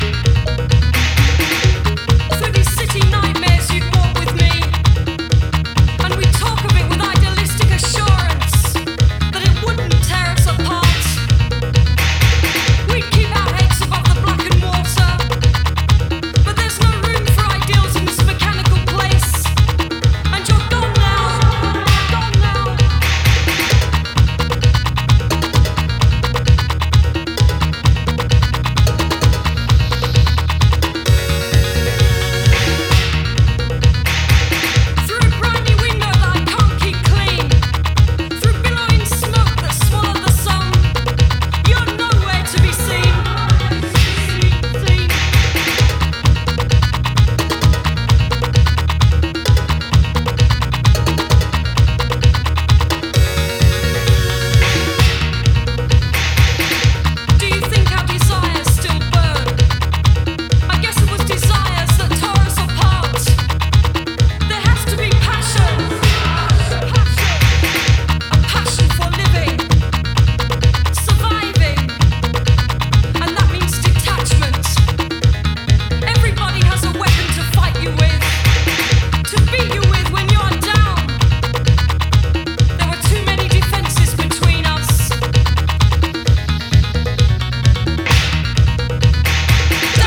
proto-house
interpretada con una mezcla de seguridad y desasosiego
ritmos hidráulicos y arpegios sombríos